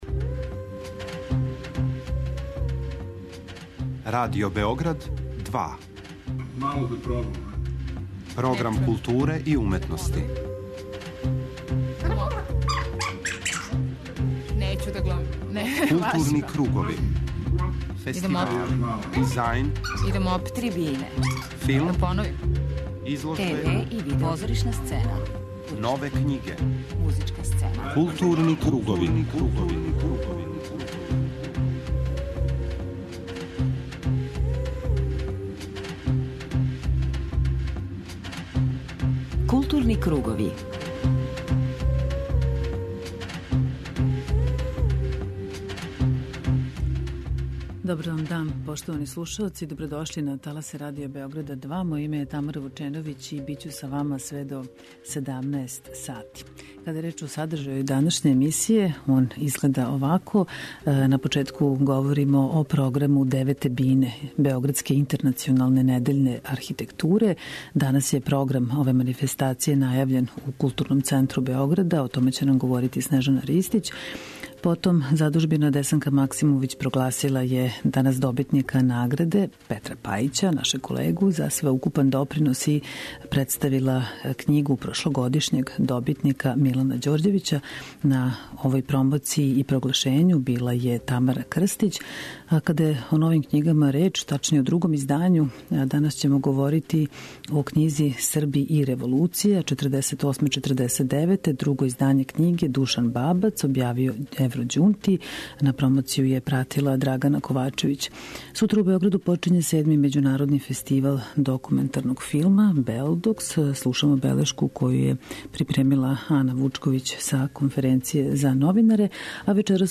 У првом делу емисије информисаћемо вас о најзначајнијим догађајима у култури Београда и Србије, а у темату 'Златни пресек', слушамо снимке из Загреба и Новог Сада - белешке са доделе 'THT награда' у Музеју сувремене умјетности, са Тједна дизајна и изложбе 'Нове аквизиције Спомен-збирке Павла Бељанског (2003-2013)' у току последње недеље њеног трајања.